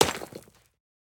Minecraft Version Minecraft Version latest Latest Release | Latest Snapshot latest / assets / minecraft / sounds / block / pointed_dripstone / land4.ogg Compare With Compare With Latest Release | Latest Snapshot